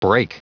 Prononciation du mot brake en anglais (fichier audio)
Prononciation du mot : brake